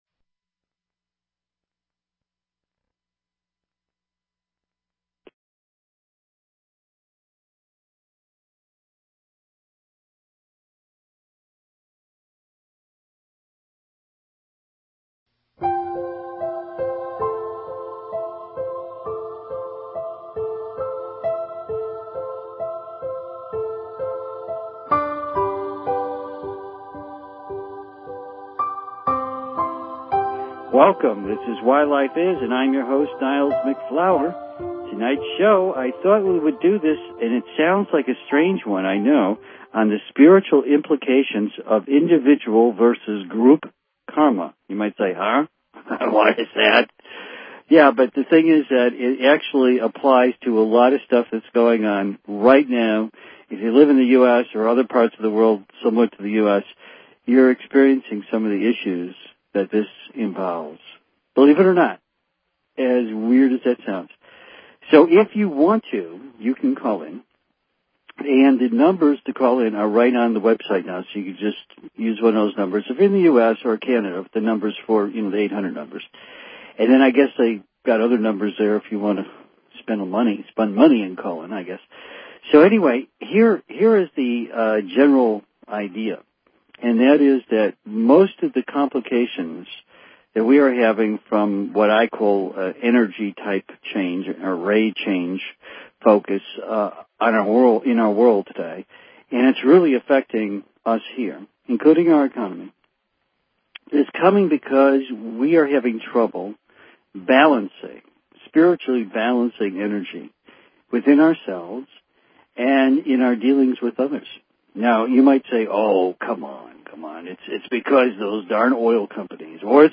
Talk Show Episode, Audio Podcast, Why_Life_Is and Courtesy of BBS Radio on , show guests , about , categorized as